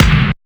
LOFI GATE K.wav